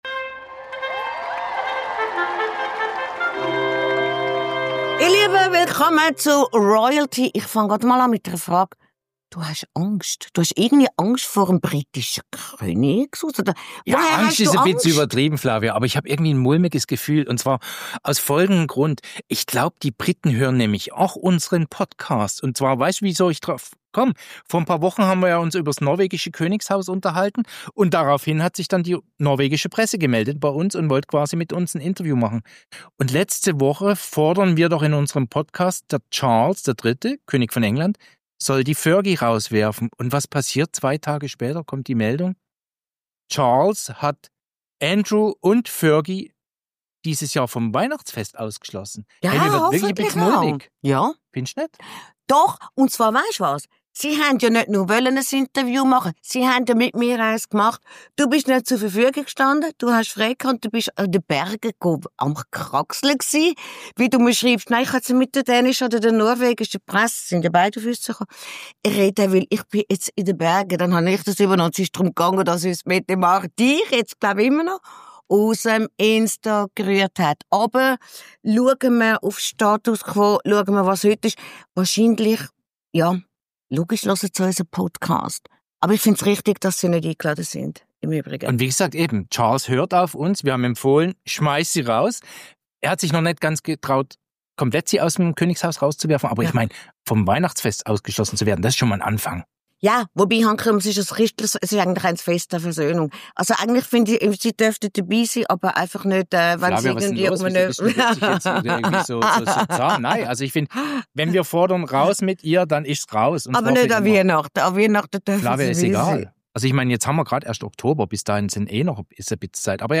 Darüber streitet unser «RoyalTea»-Expertenduo hitzig.